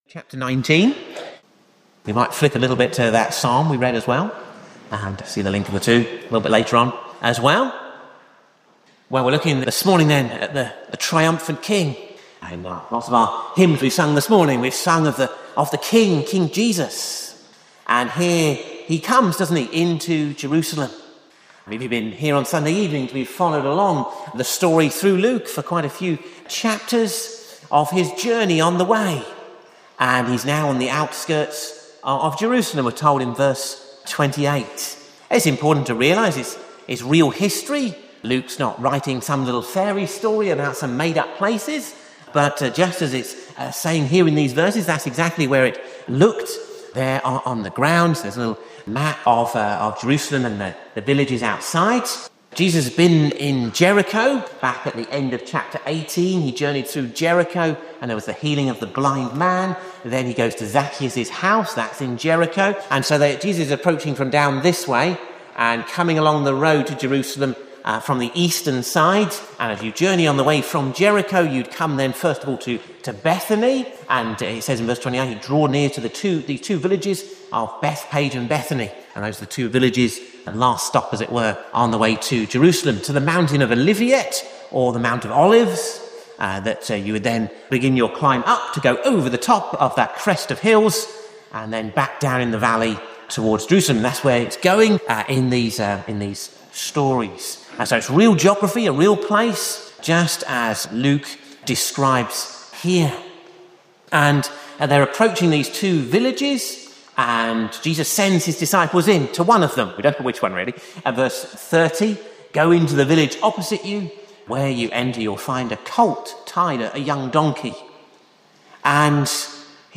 For the time being we will show our most recently recorded sermon or service here.